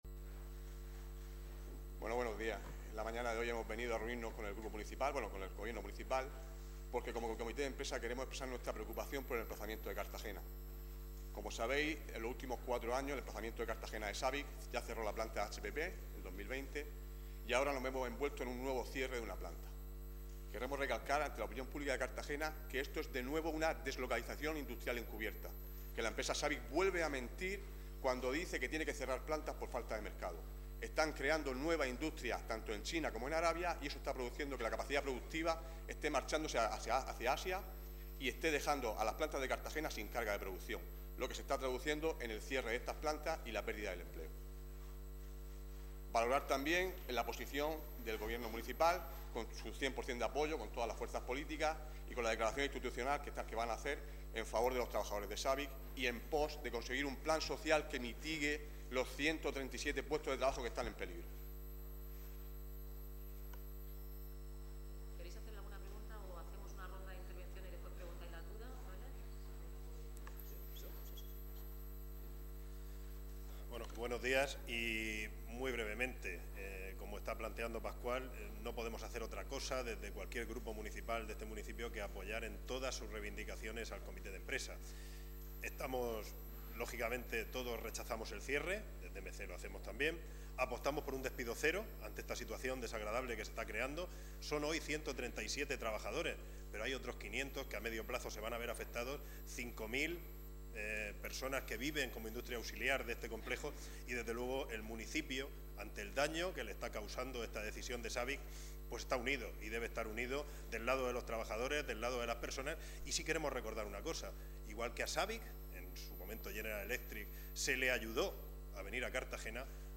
Enlace a Rueda de prensa tras la reunión con el Comité de Empresa de Sabic.